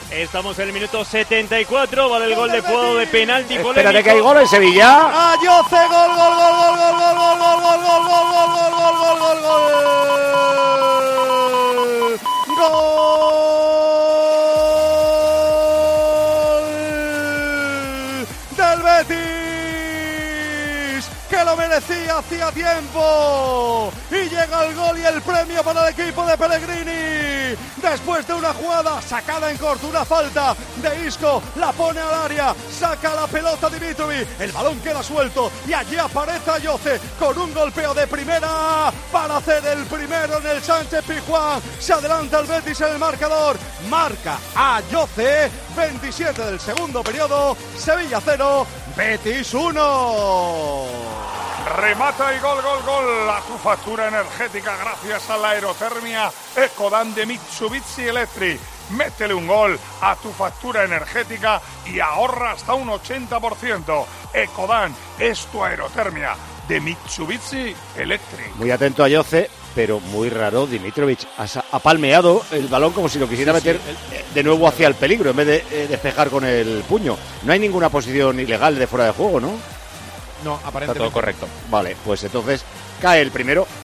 Gol de Rakitic (Sevilla, 1 - Betis, 1)